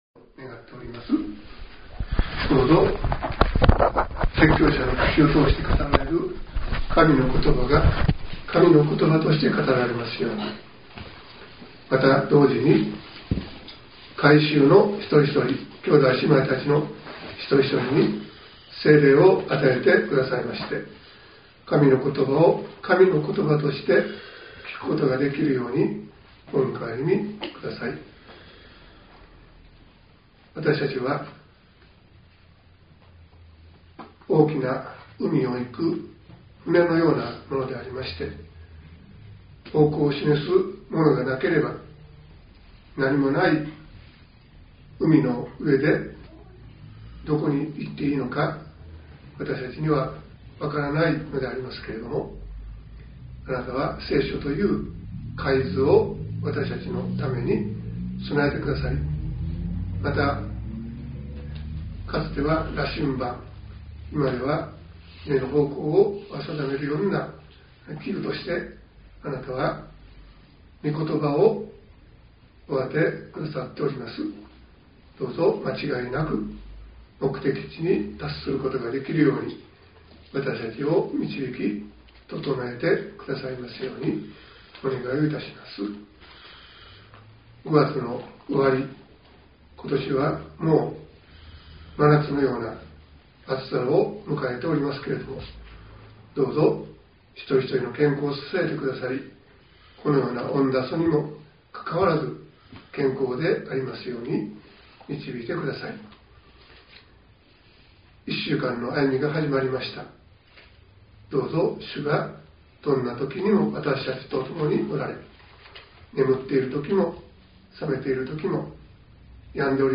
.mp3 ←クリックして説教をお聴きください。